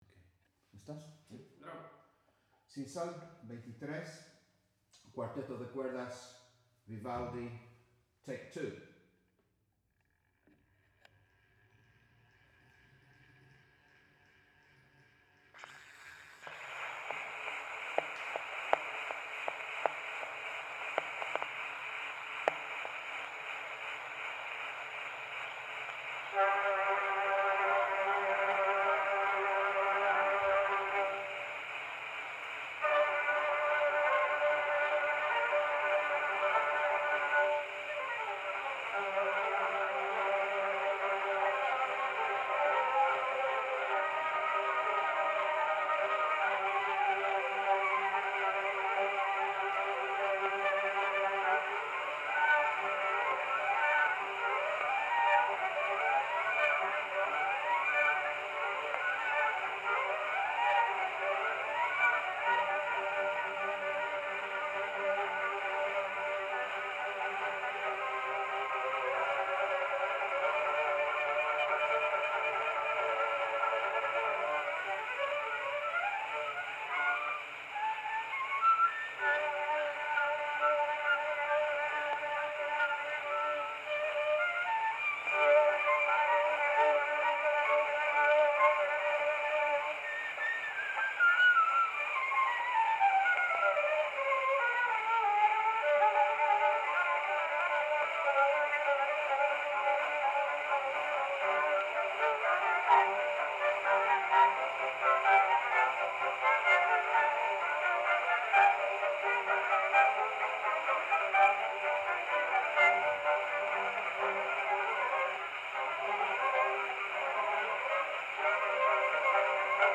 Cuarteto de cordas da Orquesta Clásica de Vigo
Original sound from the phonographic cylinder.
Recording place: Mirador do paseo dos Buxos (Island of San Simón)
Estes ruídos tamén actúan como unha malla ou un veo a través do que nos esforzamos para escoitar os sonidos gravados e este efecto de enmascaramento auditivo convídanos a escoitar a gravación con máis atención.